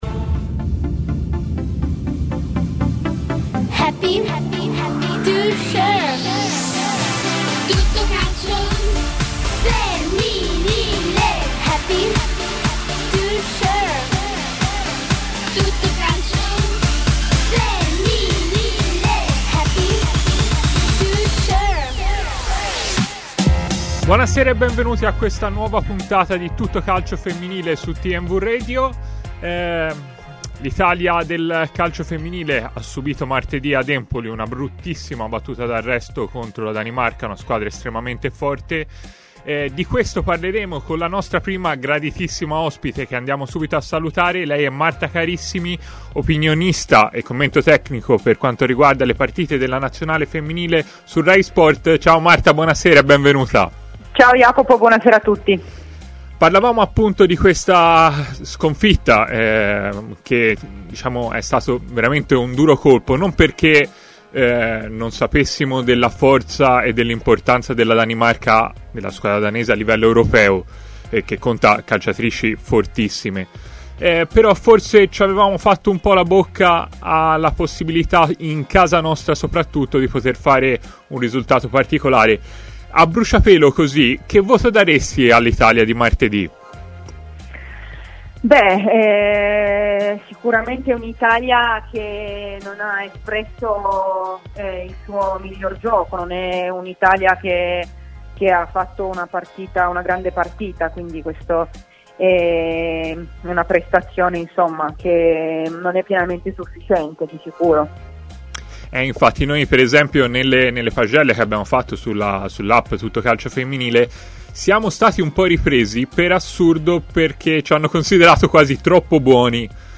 si è collegato in diretta con i microfoni di TMW Radio